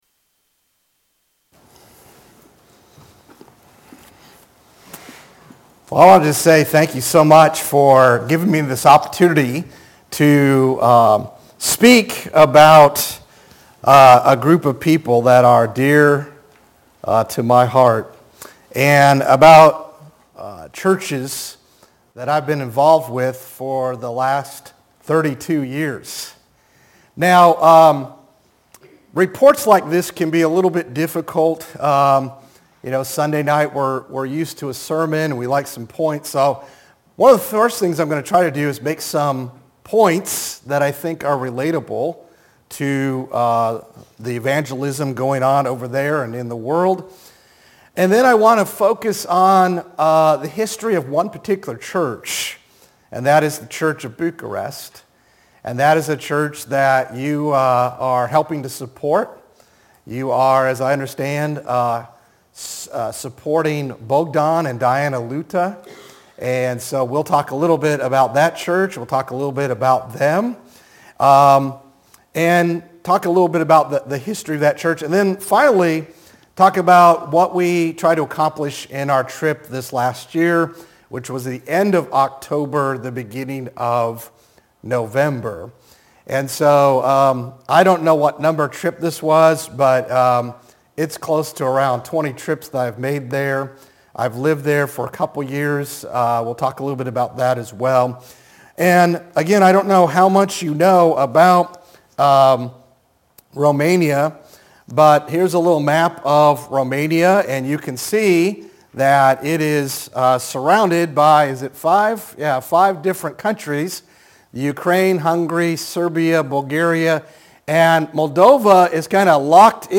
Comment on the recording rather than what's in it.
Sun PM Worship -Report on Romania